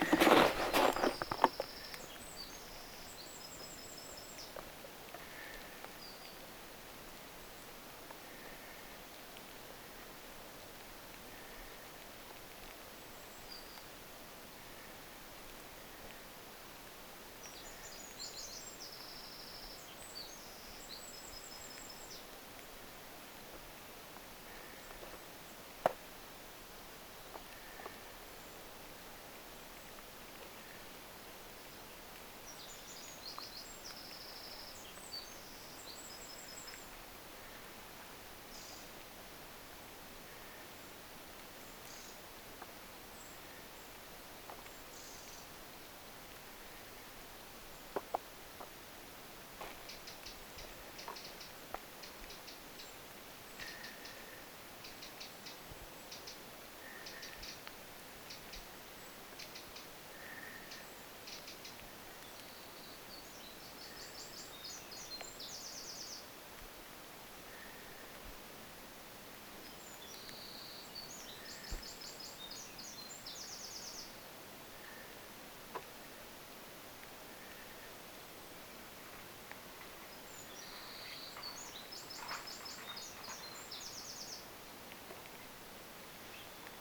tällaista ääntelyä kuului
peukaloispaikalla sinne saapuessa
kun laulaa peukaloinen täällä talvella.
Siellä soittikin lintuharrastaja äänitettä sille peukaloiselle.